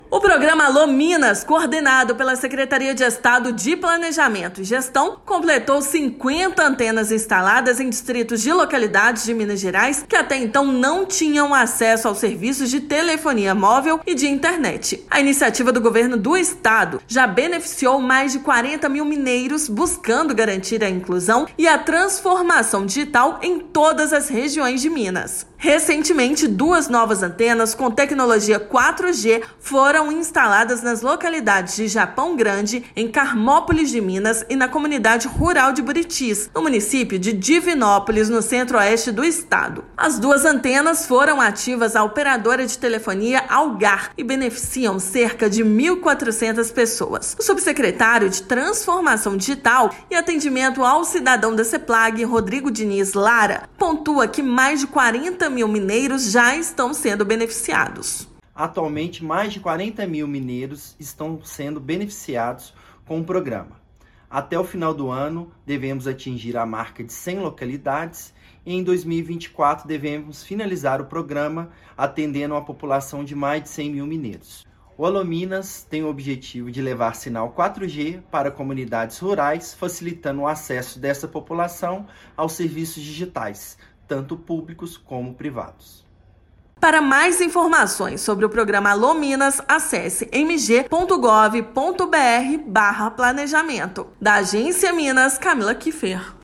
A iniciativa do Governo de Minas já beneficiou mais de 40 mil mineiros, buscando garantir a inclusão e a transformação digital em todas as regiões do estado. Ouça matéria de rádio.